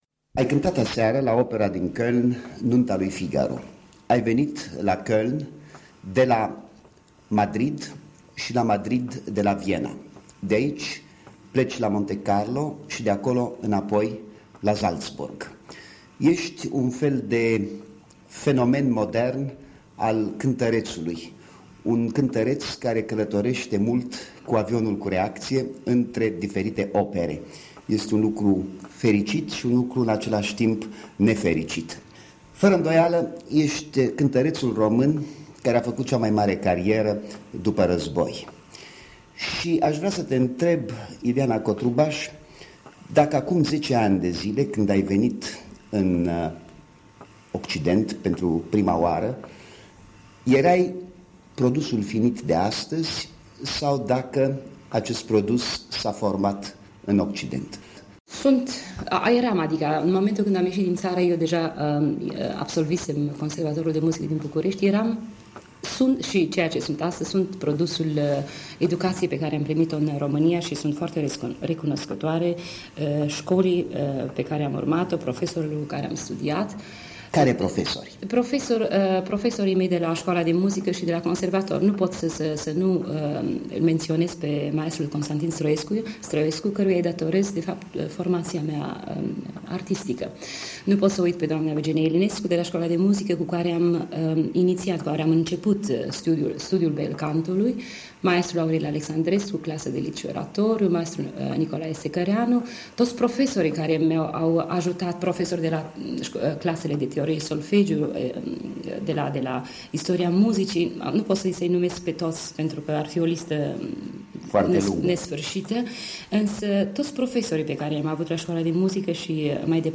Soprana Ileana Cotrubaș - un interviu cu Noël Bernard